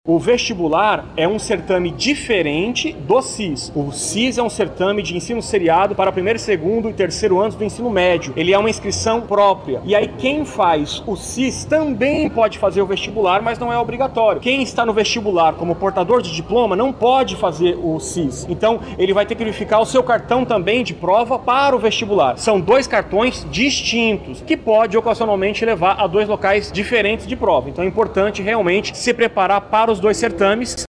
SONORA-1-VESTIBULAR-UEA-.mp3